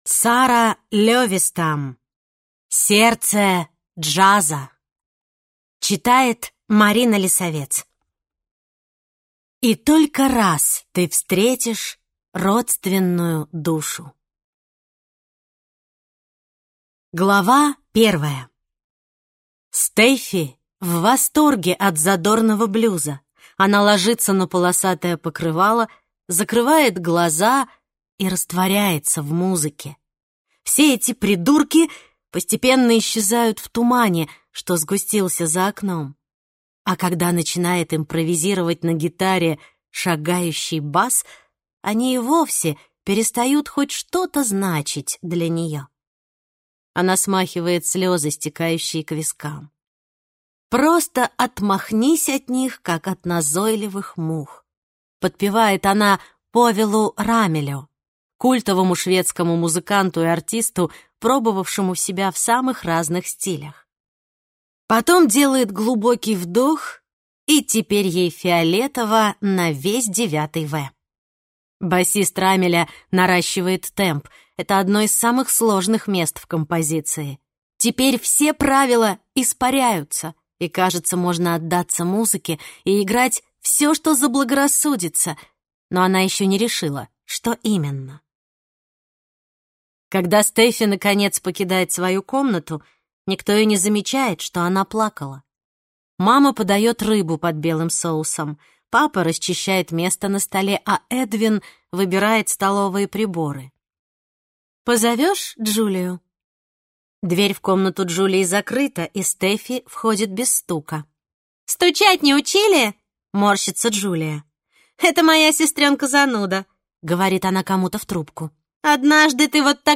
Аудиокнига Сердце джаза | Библиотека аудиокниг
Прослушать и бесплатно скачать фрагмент аудиокниги